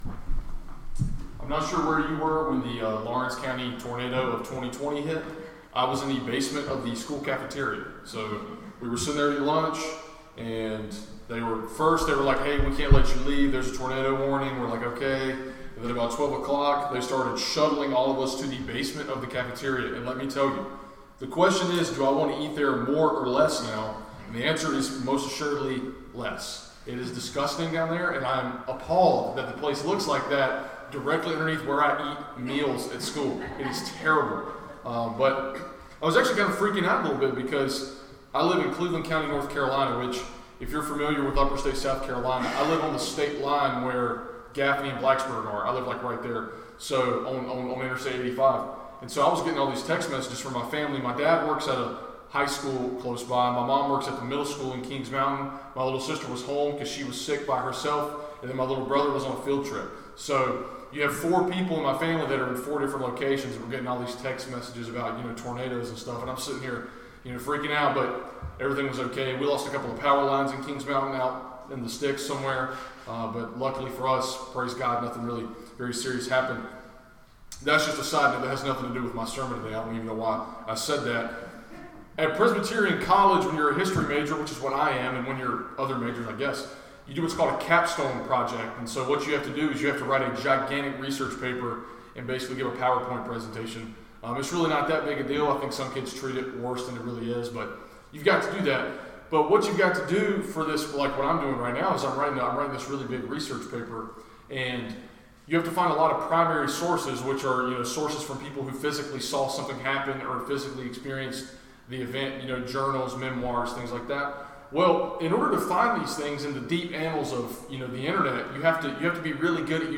Sermon — Key Words — 1 John 2:18-27